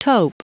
Prononciation du mot tope en anglais (fichier audio)
Prononciation du mot : tope